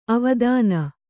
♪ avadāna